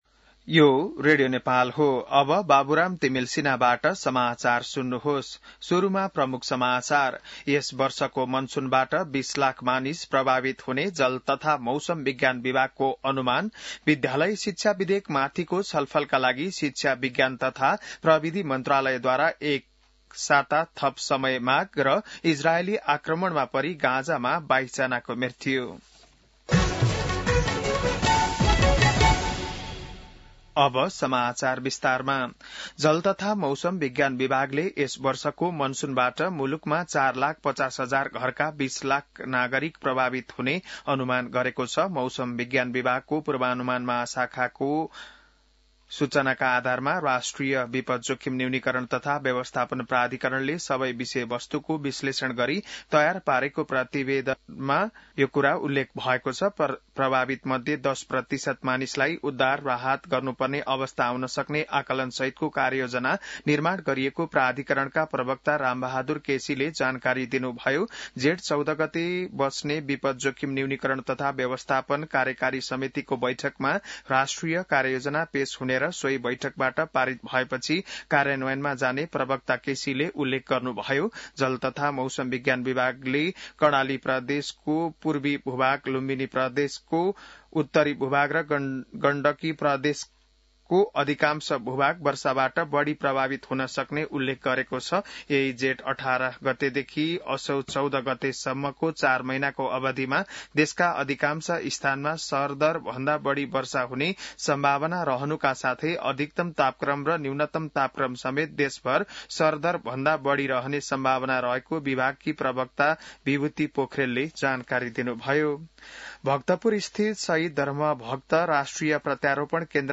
बिहान ९ बजेको नेपाली समाचार : १२ जेठ , २०८२